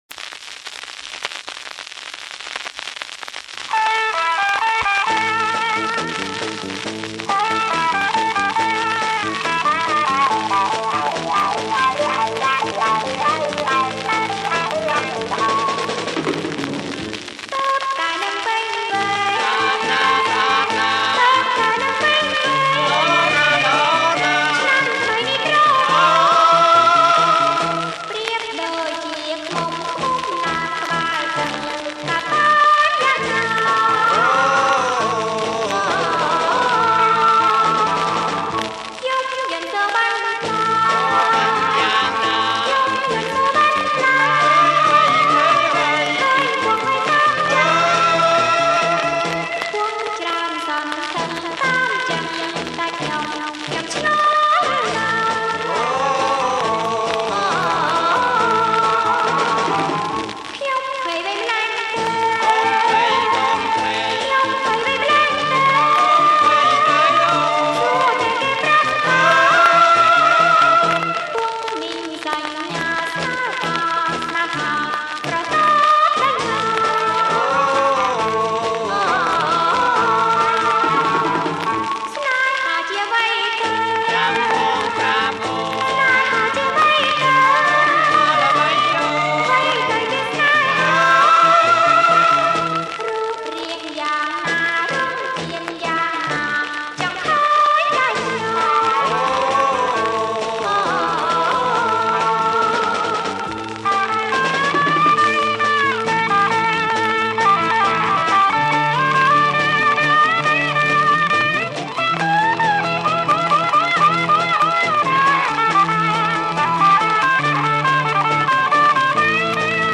• ប្រគុំជាចង្វាក់ Jerk